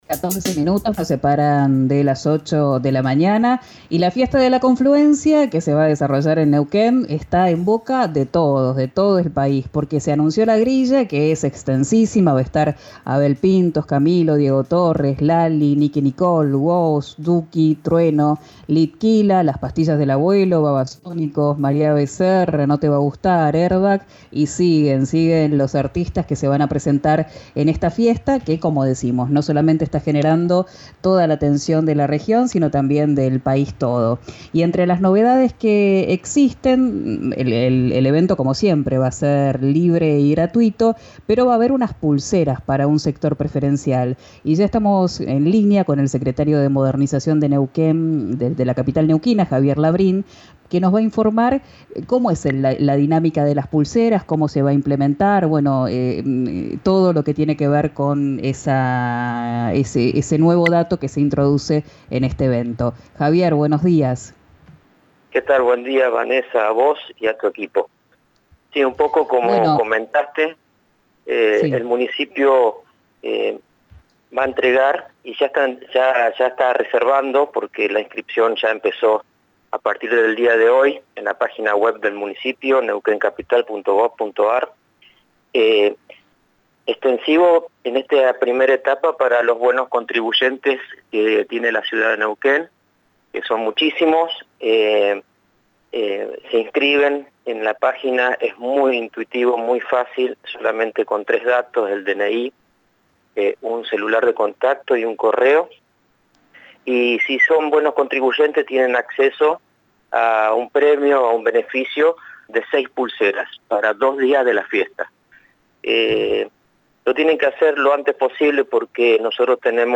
Pol Huisman dialogó con 'Quién dijo verano' por RÍO NEGRO RADIO.
En diálogo con “Quien dijo verano” por RÍO NEGRO RADIO el jefe comunal aclaró que sigue habiendo fuego subterráneo y troncos prendidos por eso continúan las tareas hoy con 70 brigadistas y medios aéreos.